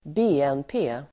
Uttal: [²b'e:en:pe:]